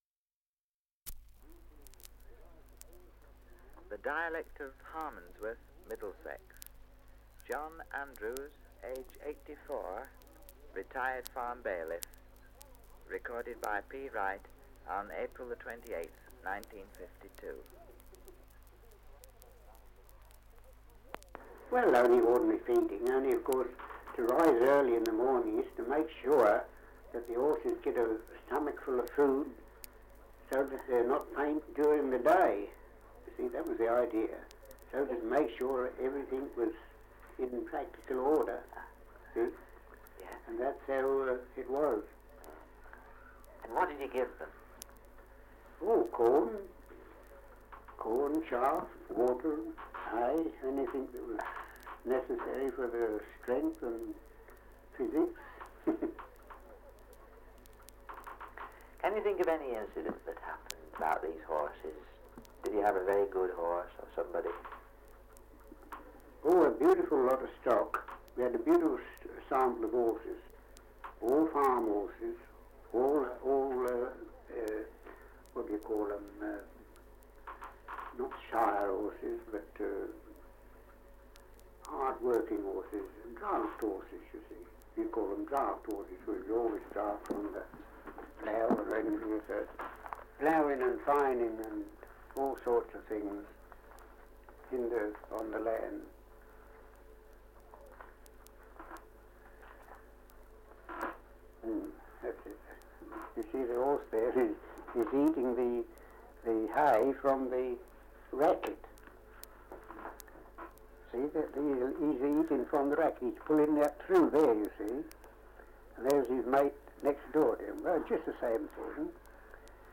Survey of English Dialects recording in Harmondsworth, Middlesex
78 r.p.m., cellulose nitrate on aluminium